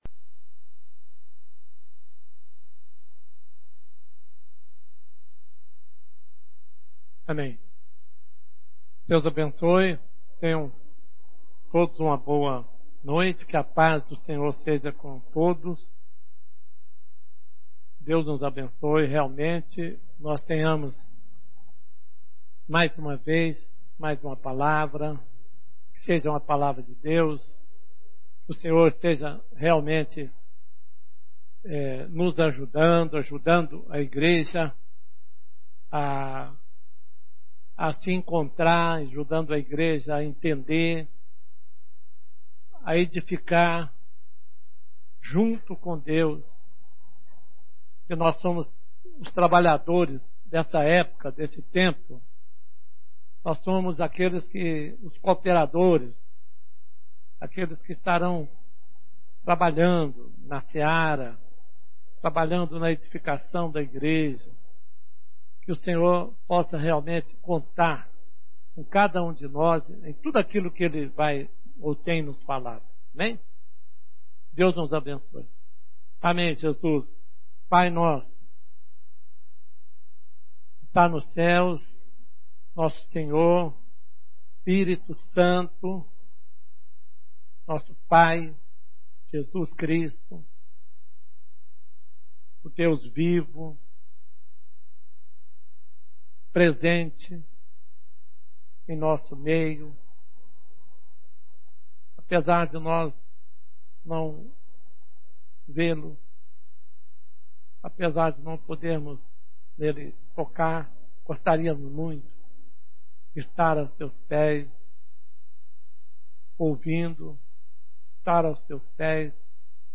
Reunião/Live Domingo a Noite 26/07/2020